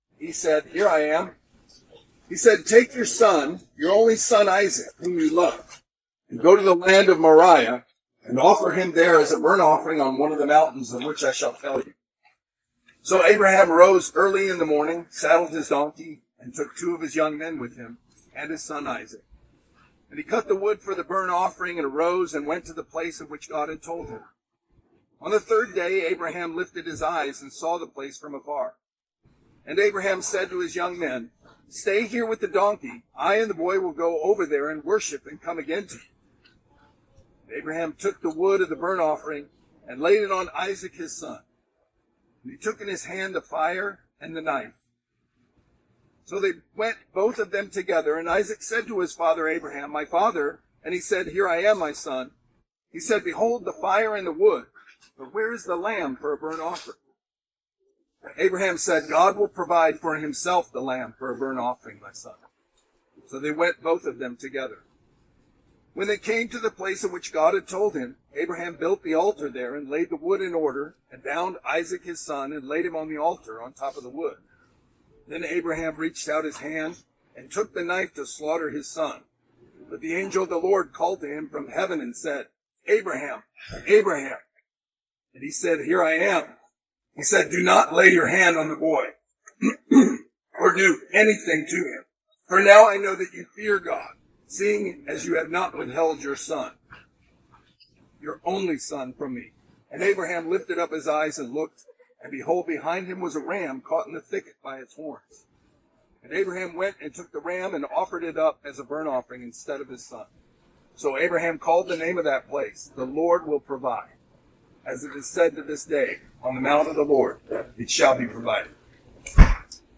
Genesis 22:1-14 Service Type: Sunday Sermon Download Files Bulletin « When Afraid